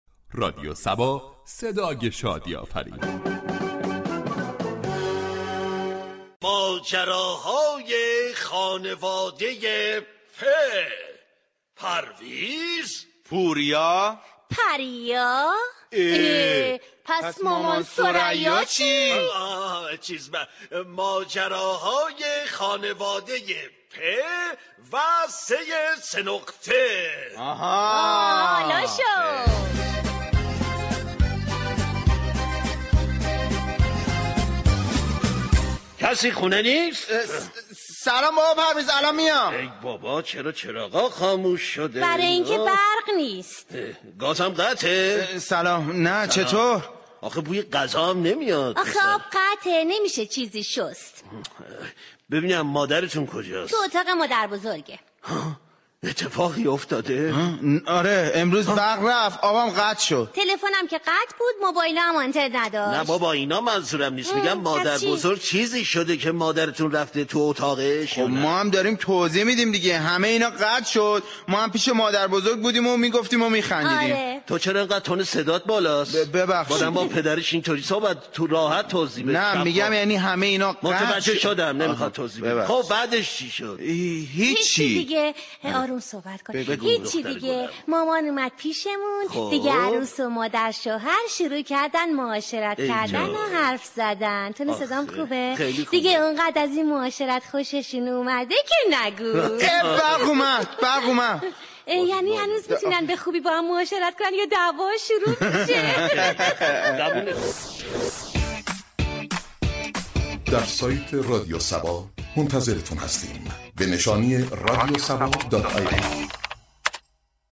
شهر فرنگ در بخش نمایشی با بیان طنز به موضوع قطعی برق پرداخته است ،در ادامه شنونده این بخش باشید.